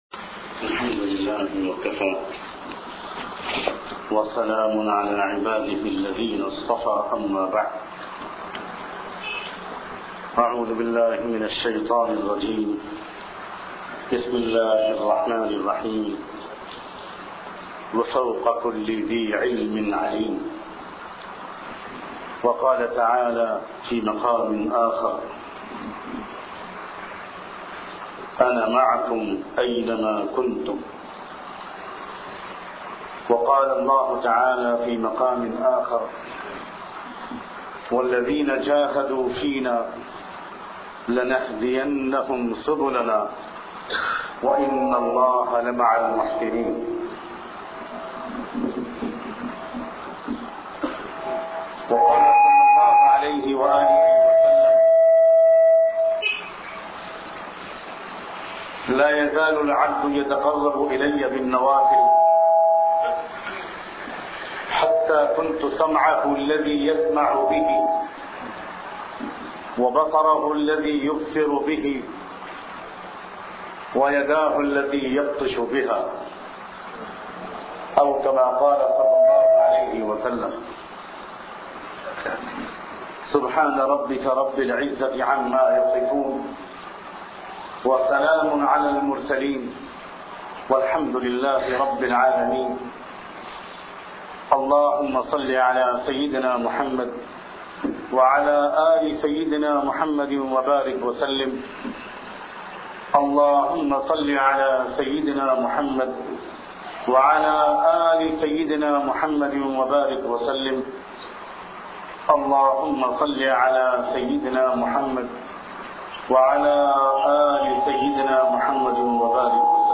Allah Ka Umoomi Sath Awr Khusoosi Sath bayan mp3 play online & download.